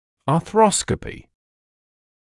[ɑː’θrɔskəpɪ][аː’сроскэпи]артроскопия